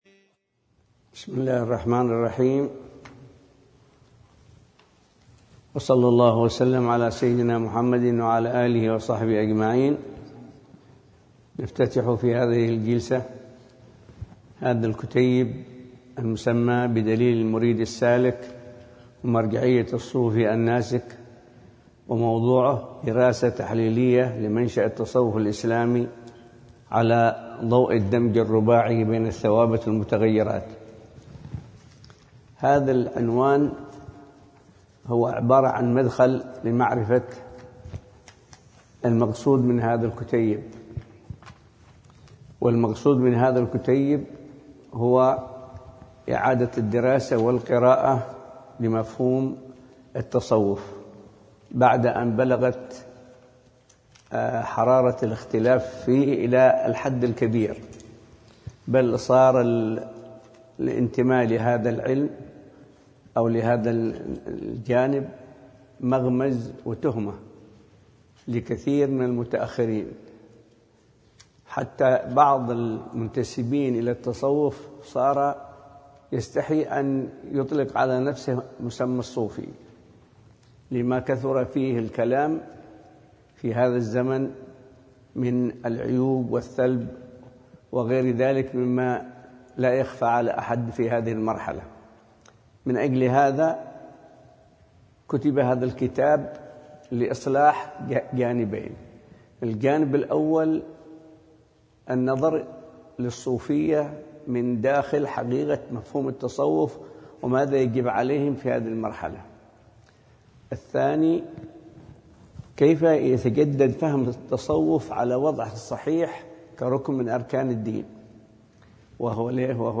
شرح كتاب دليل المريد السالك ومرجعية الصوفي الناسك | المحاضرة الأولى